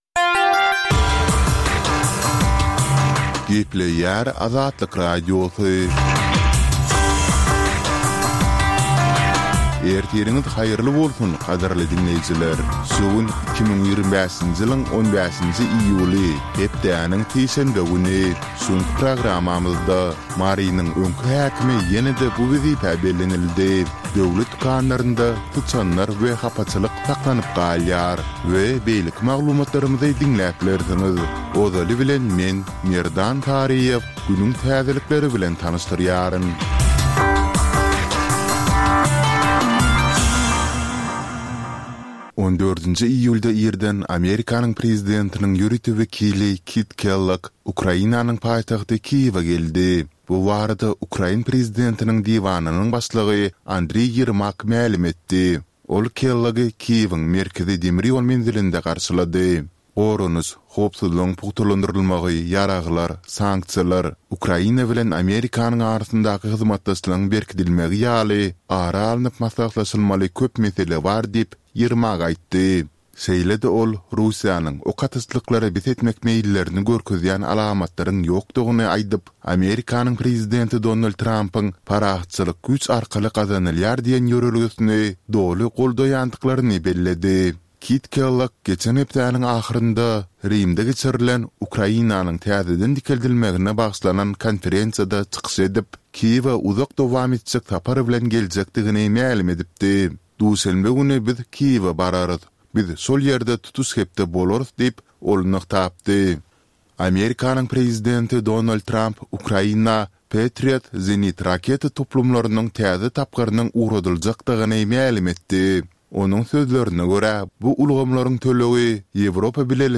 Dünýäniň dürli regionlarynda we Türkmenistanda şu günki bolan we bolup duran soňky wakalar barada gysgaça habarlar.